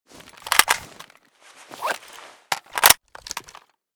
akm_reload.ogg.bak